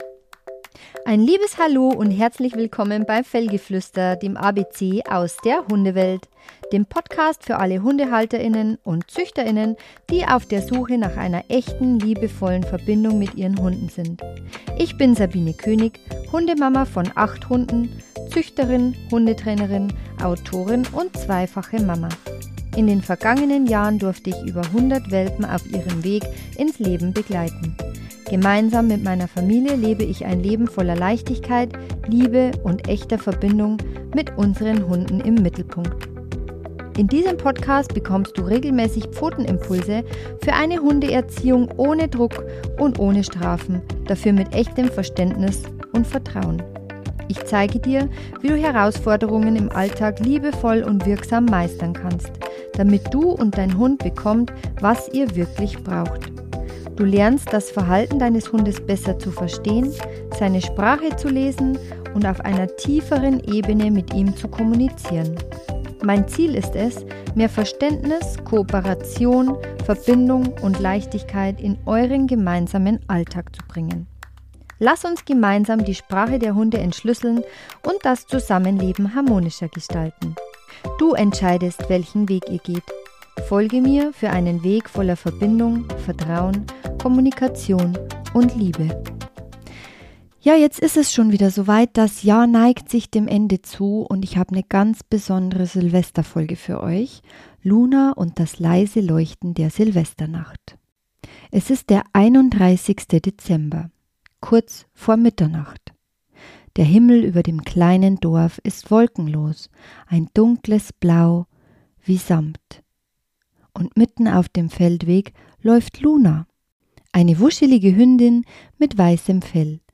Eine ruhige Geschichte über Nähe, Mut und Vertrauen zum Jahreswechsel